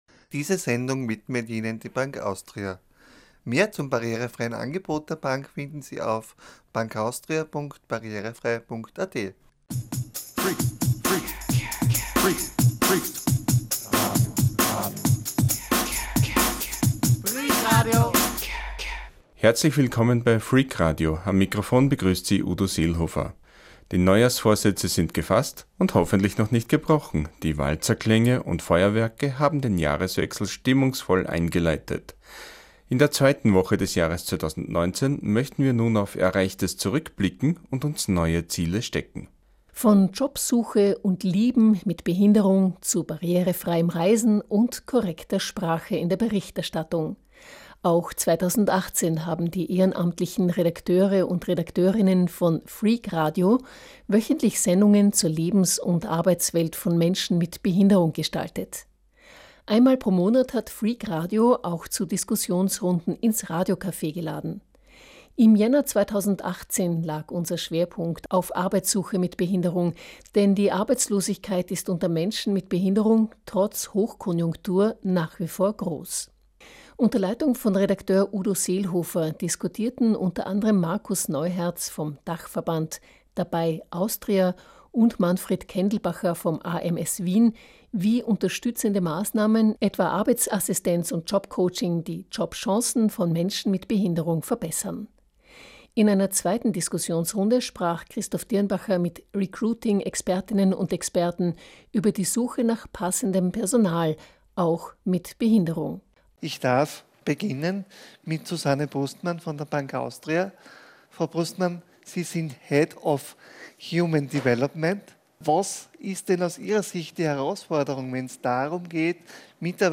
Freak Online - die Webplattform von Freak Radio, dem Radio über den barrierefreien Lifestyle.